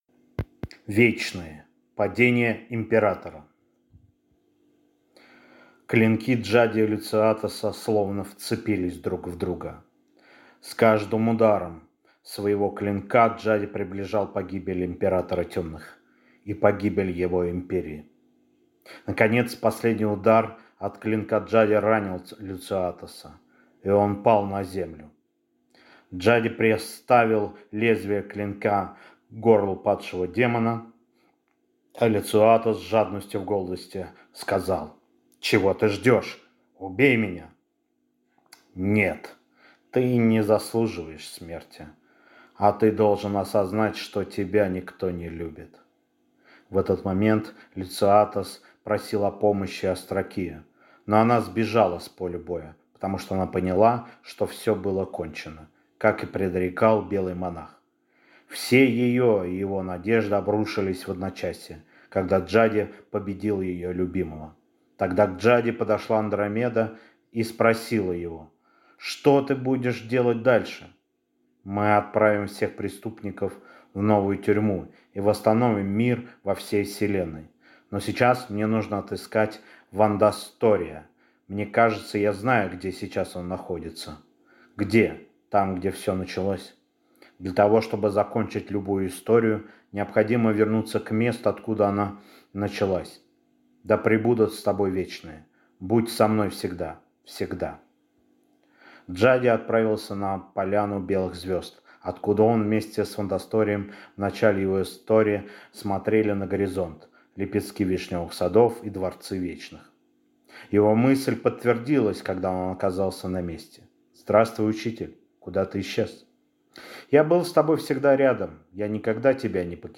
Аудиокнига Вечные.